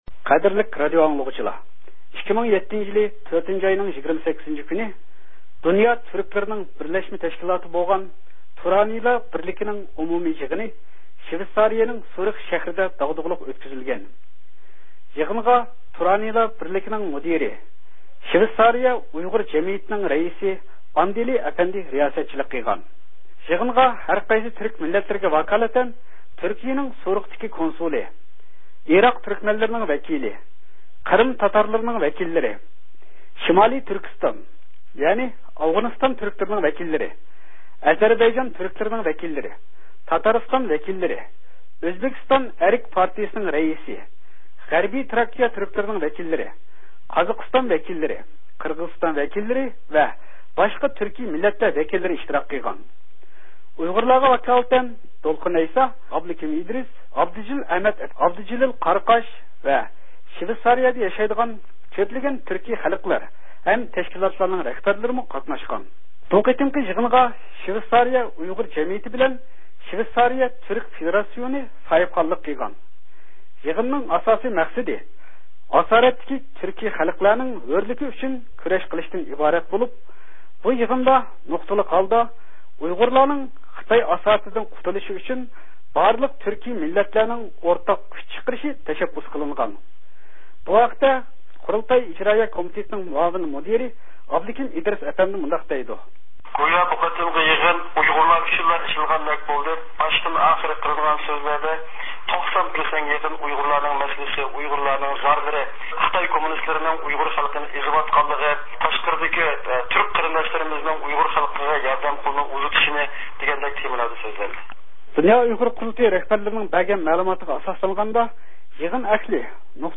بۇ يىغىن توغرىسىدىكى تەپسىلى مەلۇماتىنى ئاڭلاڭ